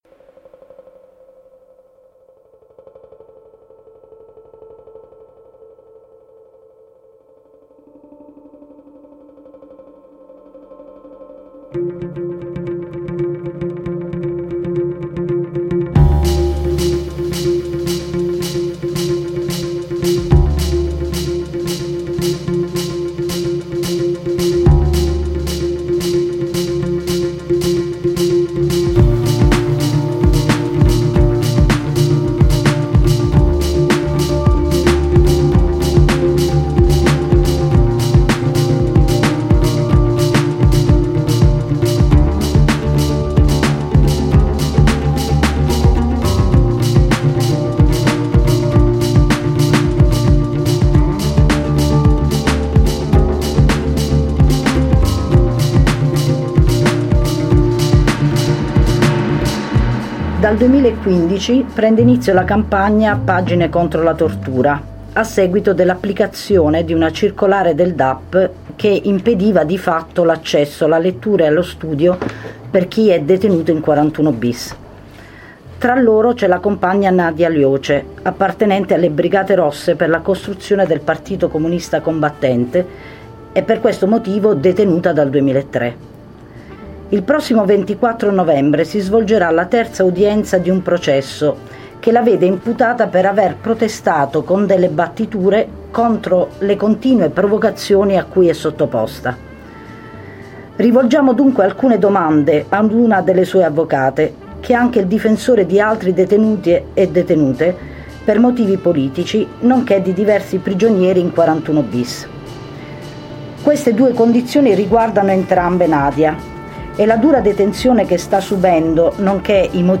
Intervista sul 41bis
Durante la trasmissione "Silenzio Assordante" è andato in onda questo contributo a cura della campagna "Pagine contro la tortura", in vista della mobilitazione del 24 novembre a L'Aquila.